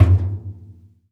IMPACT_Metal_Barrel_Subtle_mono.wav